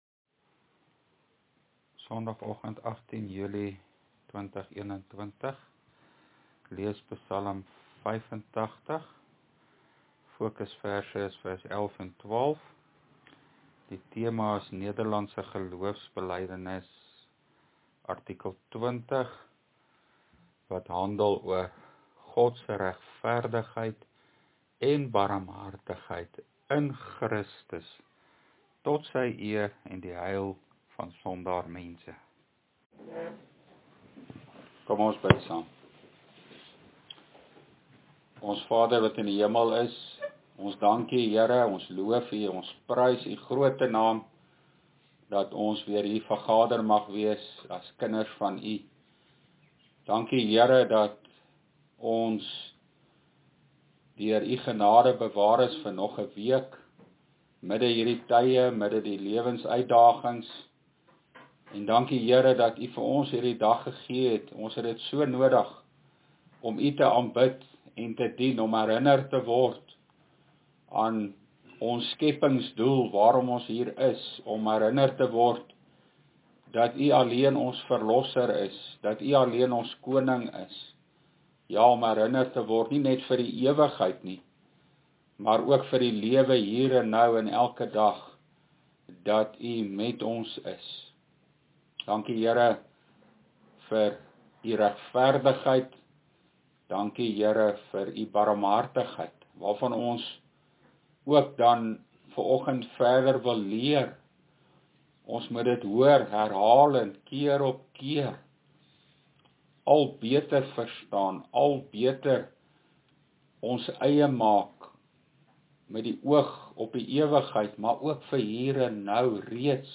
LEERPREDIKING: NGB artikel 20